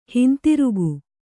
♪ hintirugu